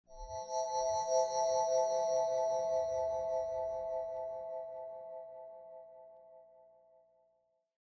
魔力が静かに集まる LAW 01
/ F｜演出・アニメ・心理 / F-30 ｜Magic 魔法・特殊効果
ウワワーン